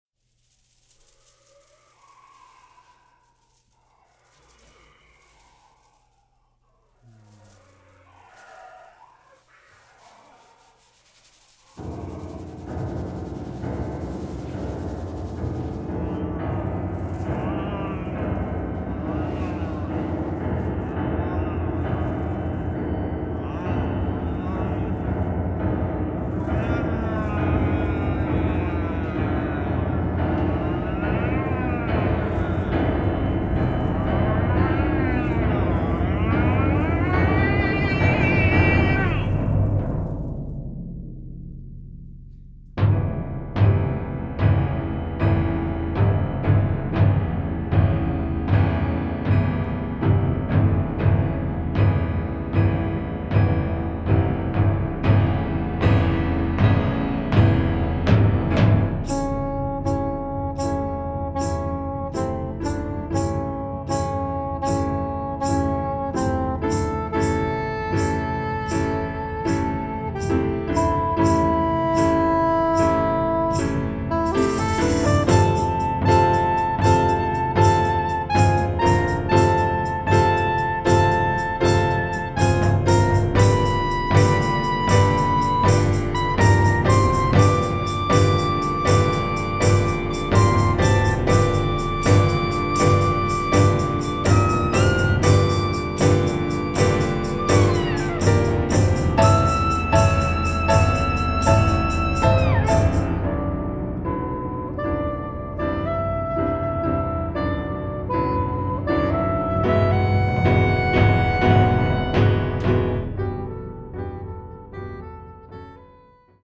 soprano saxophone, piano and two percussionists premiere
new music ensemble